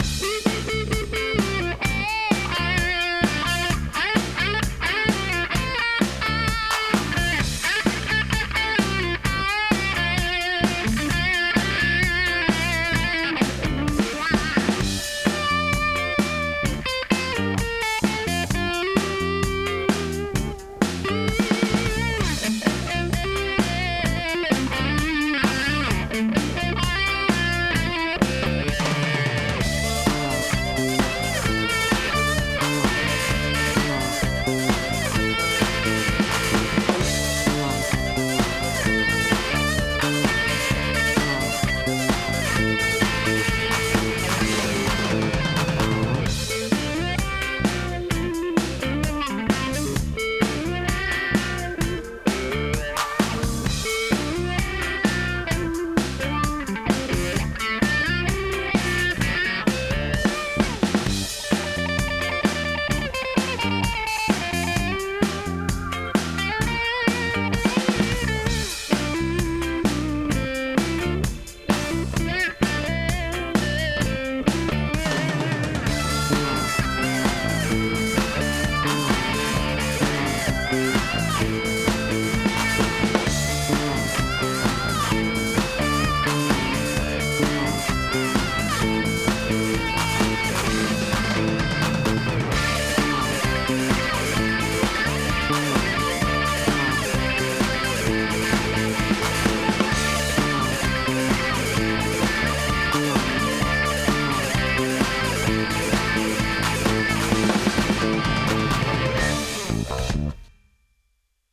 • Жанр: Фолк
Инструментальная композиция